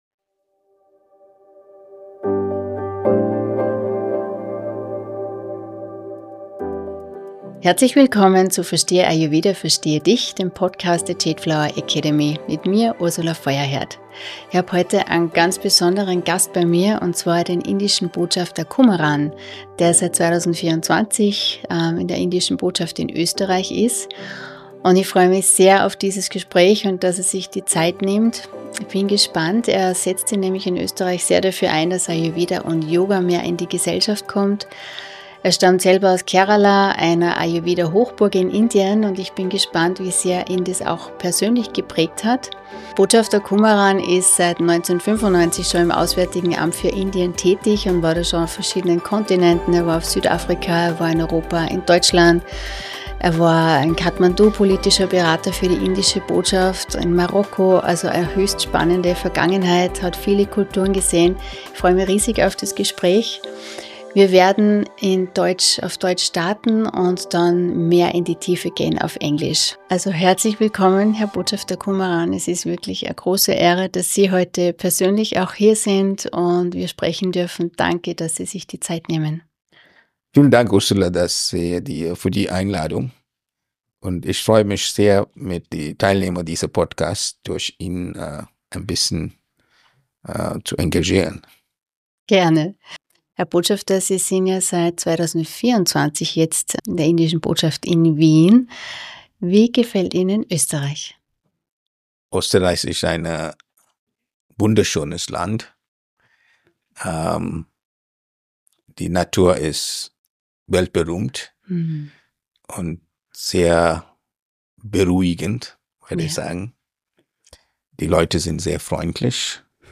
In dieser besonderen Folge habe ich die Ehre mit dem indischen Botschafter Kumaran zu sprechen, der seit 2024 in Österreich tätig ist. Ein inspirierendes Gespräch über Ayurveda, gelebte Weisheit und wie Ayurveda und Schulmedizin Hand in Hand gehen können....